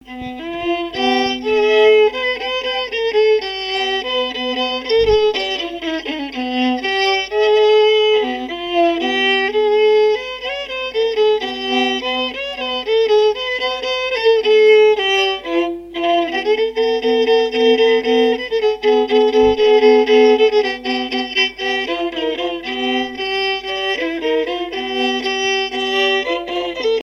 danse : polka boulangère
répertoire musical au violon
Pièce musicale inédite